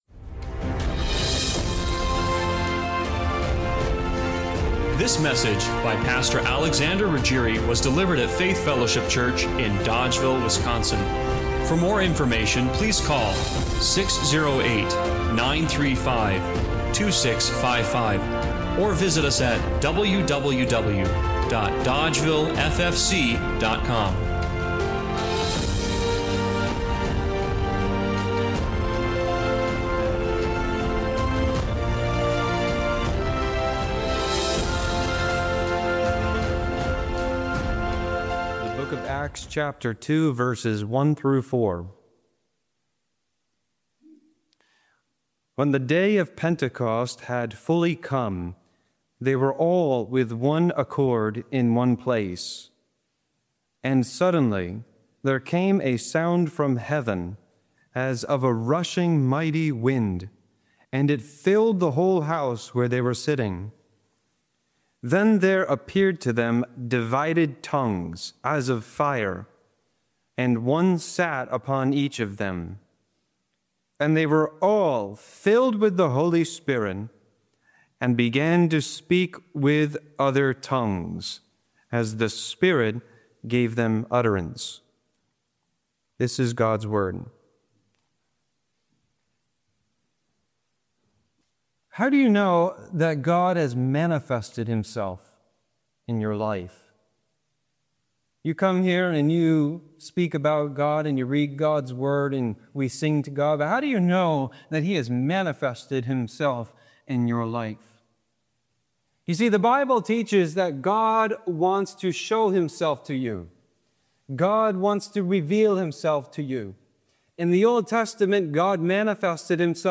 Acts 2:1-4 Service Type: Sunday Morning Worship What happens when the living Spirit of God truly takes hold of a person?